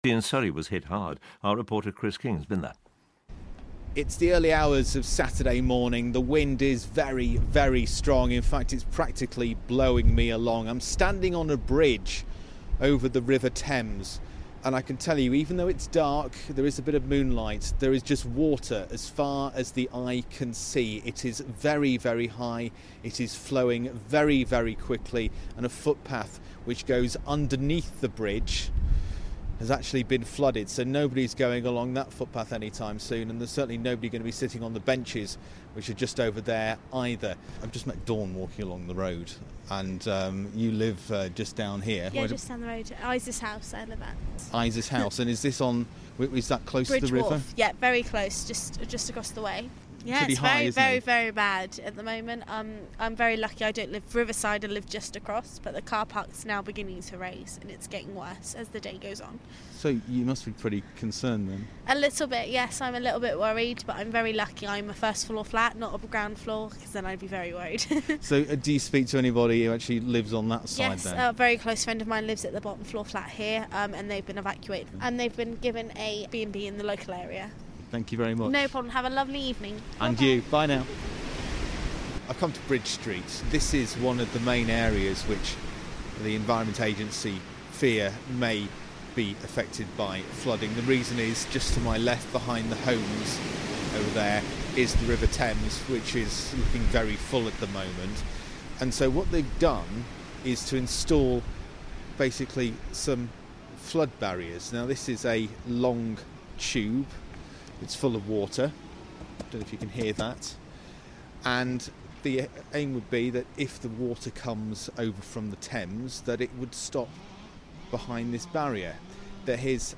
On a stormy night in February I was sent out to flood-hit Chertsey to find out what was going on.